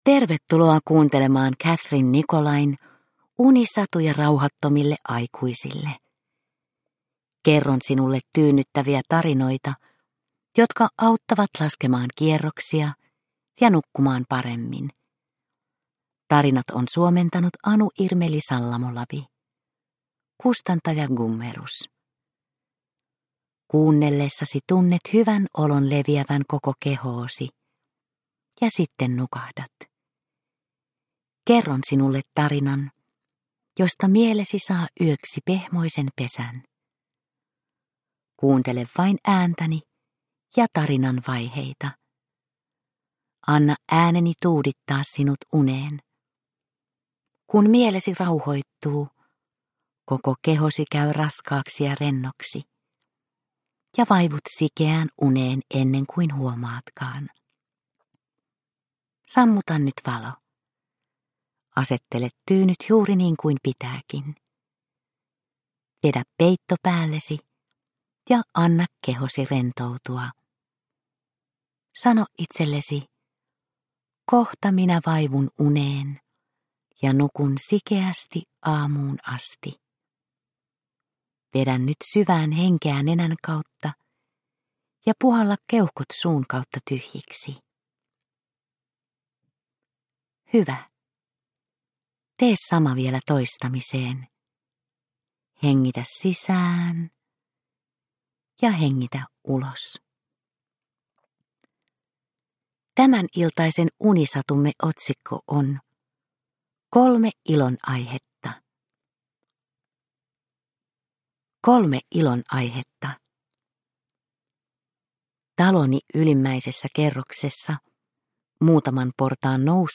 Unisatuja rauhattomille aikuisille 29 - Kolme ilonaihetta – Ljudbok – Laddas ner